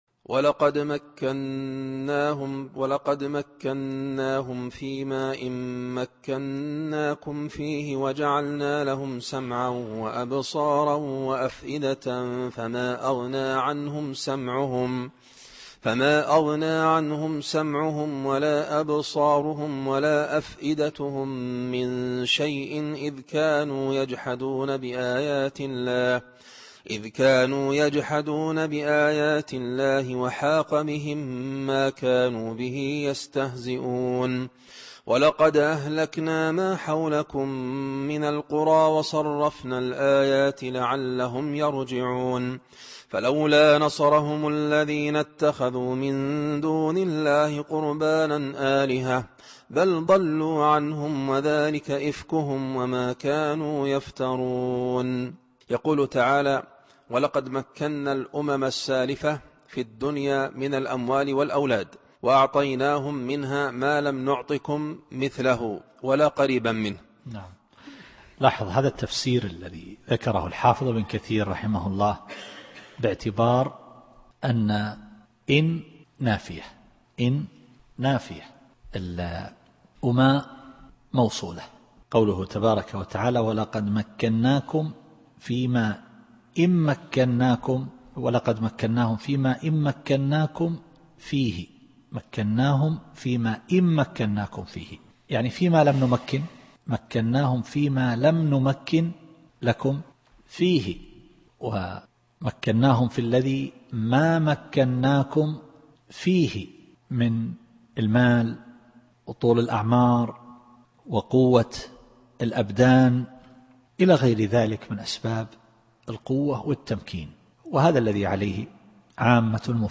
التفسير الصوتي [الأحقاف / 26]